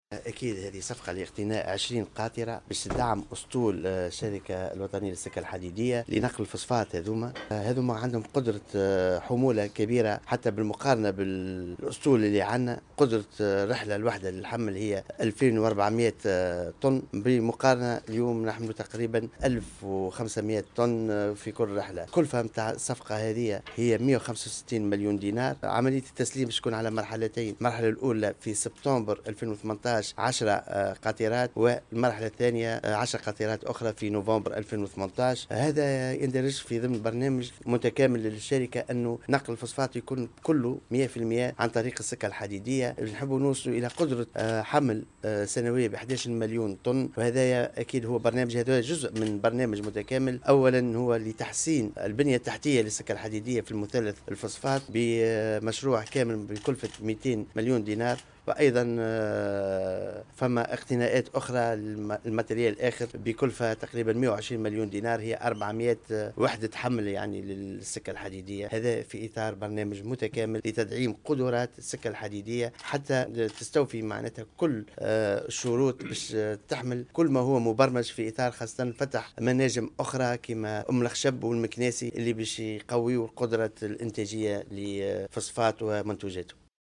قال وزير النقل أنيس غديرة في تصريح لمراسل الجوهرة "اف ام" اليوم الإثنين 5 ديسمبر 2016 على هامش ابرام صفقة اقتناء 20 قاطرة لنقل الفسفاط بين الشركة الوطنية للسكك الحديدية التونسية والولايات المتحدة الأمريكية إن هذه الصفقة ستدعم أسطول السكك الحديدة لنقل الفسفاط بقدرة حمولة كبيرة مقارنة بالأسطول الموجود على حد قوله.